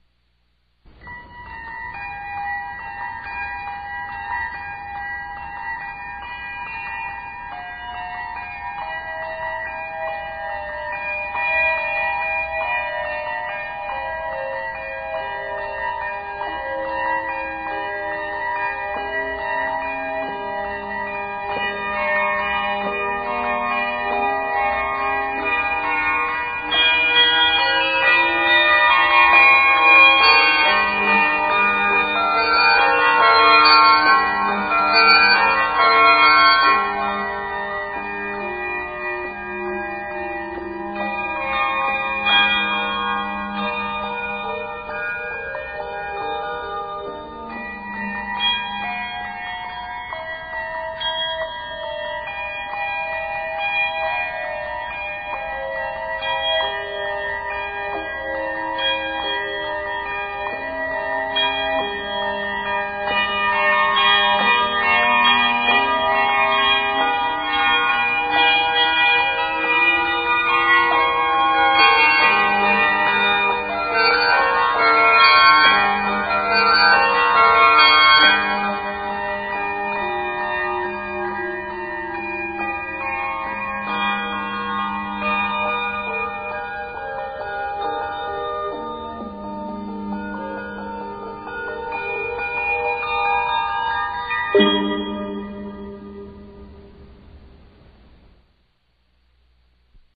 Octaves: 3-4